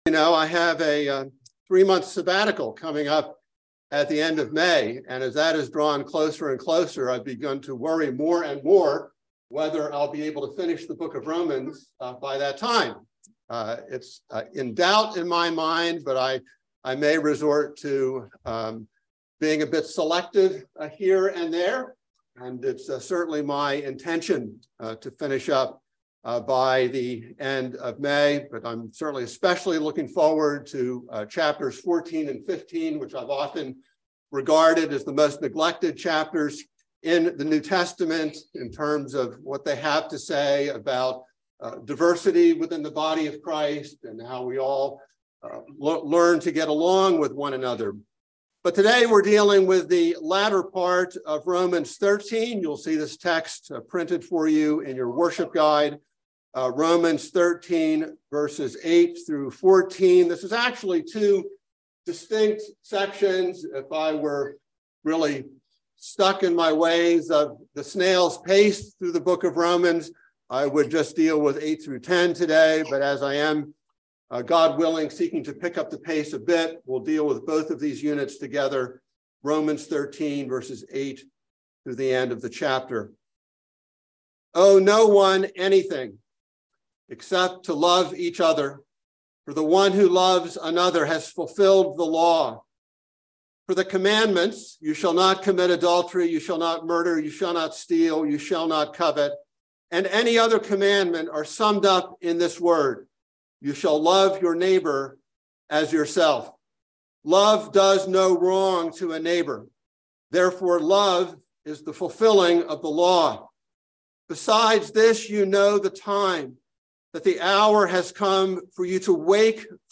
by Trinity Presbyterian Church | Mar 21, 2023 | Sermon